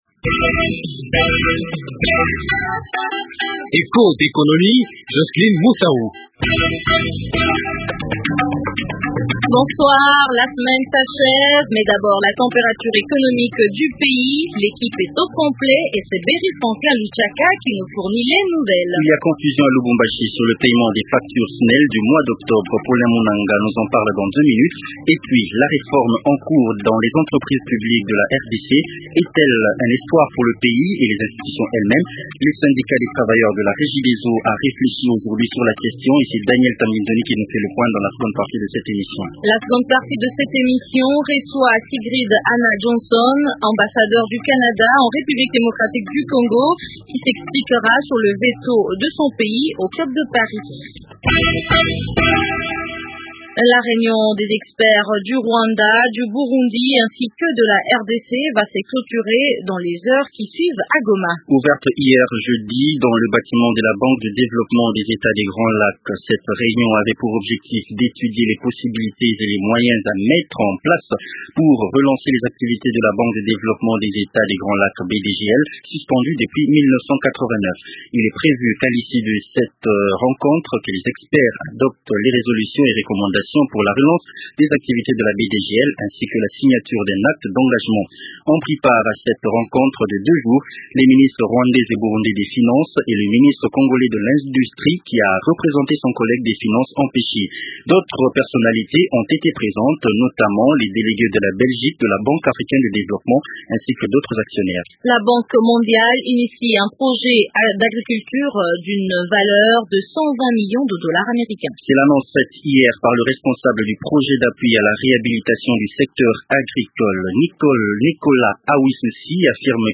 Ottawa n’encouragera pas d’autres investisseurs canadiens à venir RDC, tant que le climat des affaires dans ce pays ne se sera amélioré. L’ambassadeur du Canada en RDC, Sigrid Anna Johnson, est l’invité du jour.